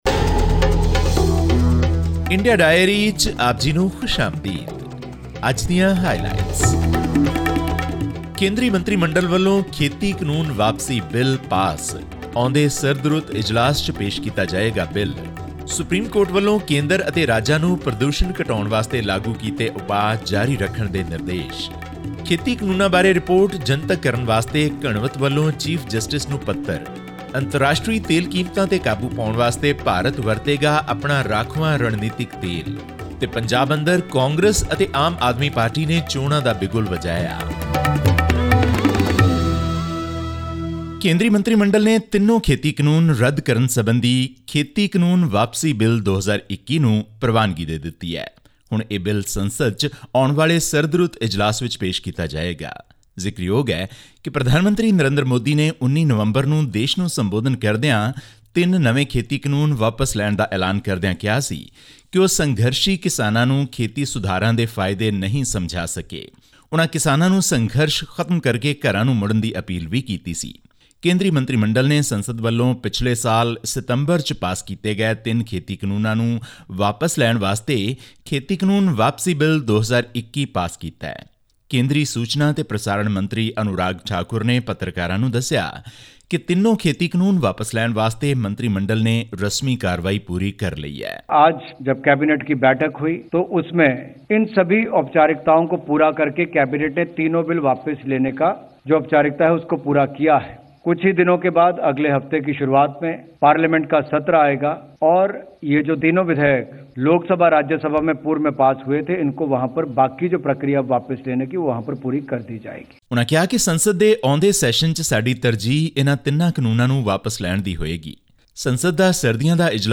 The Union Cabinet on 24 November approved a bill to repeal the three farm laws, which triggered protests at New Delhi's borders by thousands of farmers. All this and more in our weekly news segment from India.